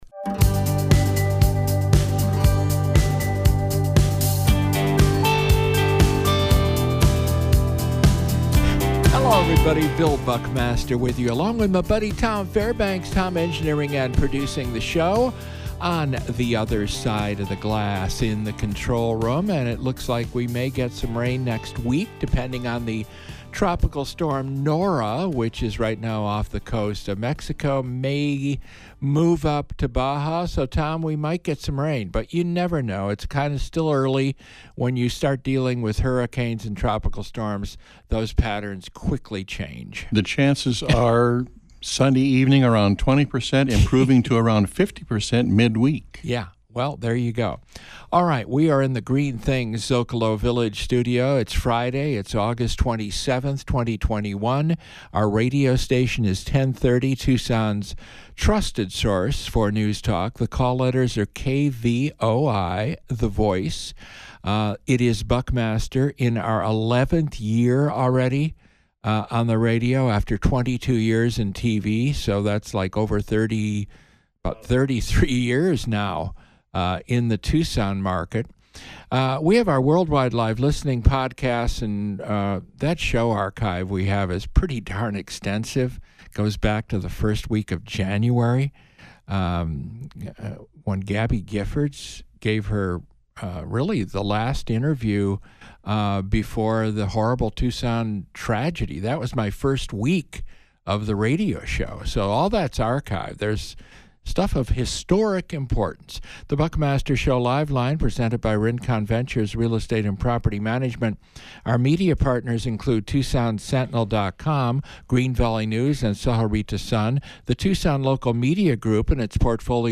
The Friday Focus interview is with U-S Rep. Raul Grijalva (D-AZ-District 3).